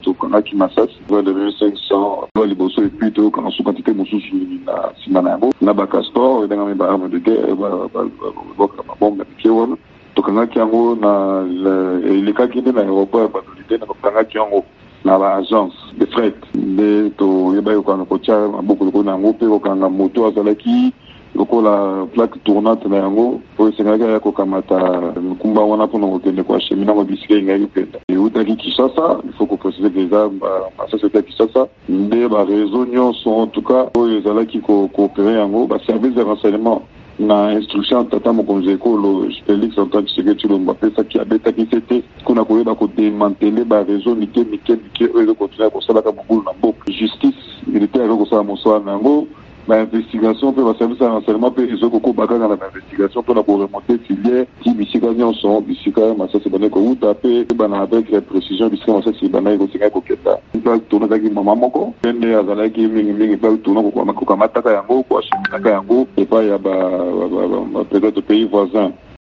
Misala mya bokengi ya RDC mitiaki maboko na minduki ya bitumba koleka 1.500 na ba bombes Castors bato baiike balingaki kotinda na Centrafrique na kobombama mpo na kopesama na mangomba ya basimba minduki. VOA Lingala epesaki malabo na mokambi ya Nord-Ubangi, Izato Nzege Koloke.